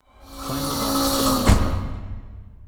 “Stingerstrike” Clamor Sound Effect
Can also be used as a car sound and works as a Tesla LockChime sound for the Boombox.